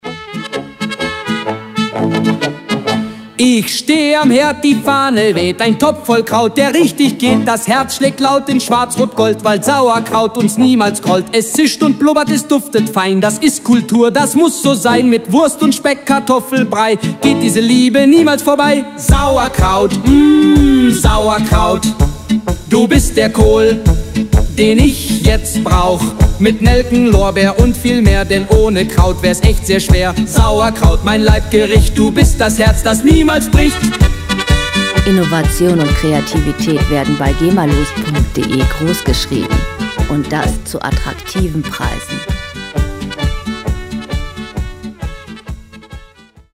Gema-freie Schlager Pop Musik
Musikstil: Bayerische Polka
Tempo: 125 bpm
Tonart: D-Dur
Charakter: lustig, humorvoll